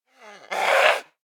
DayZ-Epoch/SQF/dayz_sfx/zombie/spotted_5.ogg at 9ae5e80a43cde43b6504dcde4d5a9df850c61844